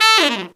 Index of /90_sSampleCDs/Zero-G - Phantom Horns/TENOR FX 2